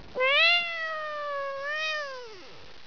If you pet him with a click of your mouse, he'll meow for you.